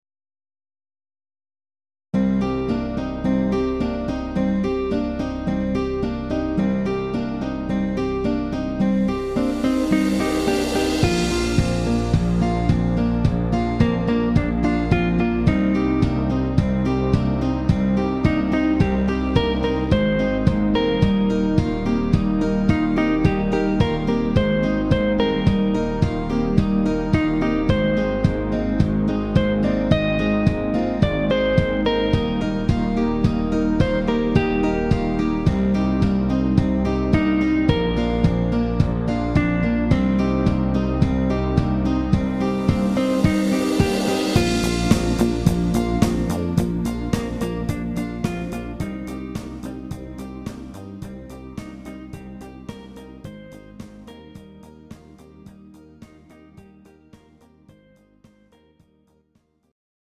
Ballads Music